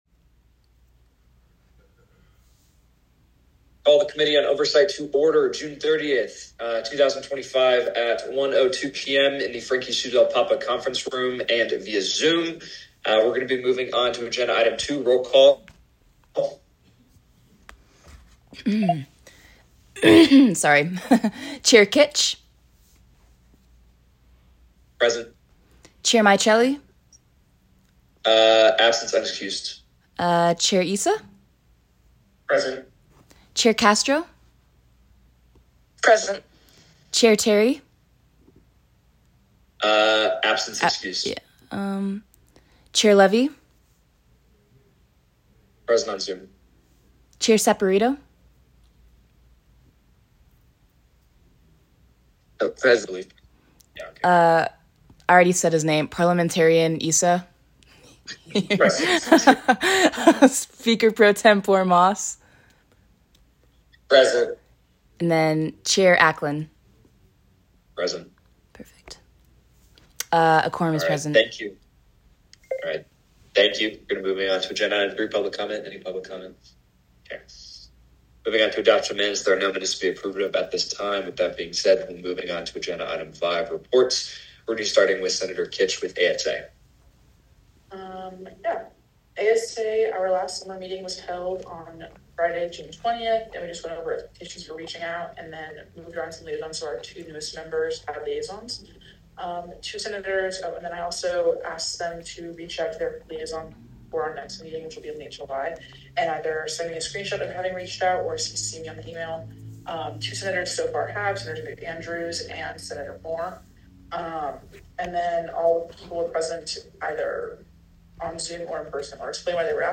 Meeting Type : Oversight Committee
Location : Frankie Sue Del Papa Conference Room